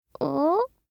알림음 8_BoyOh1.mp3